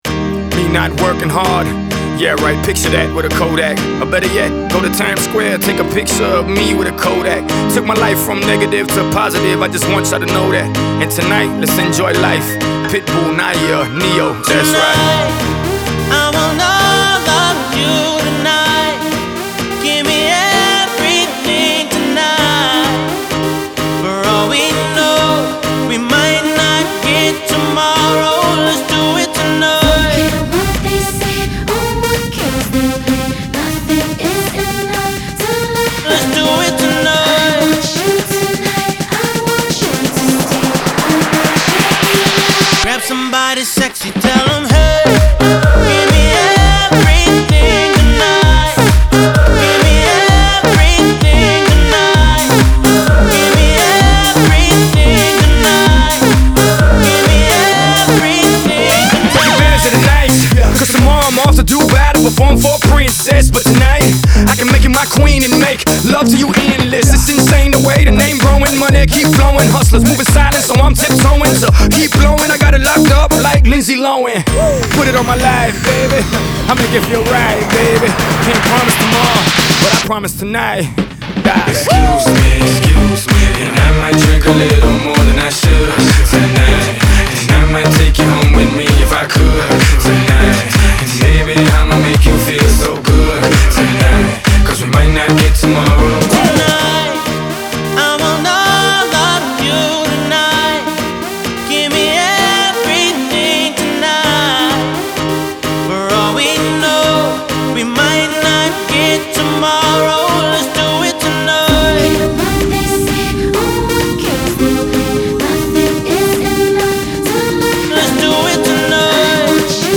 Pop Rap, Synth-Pop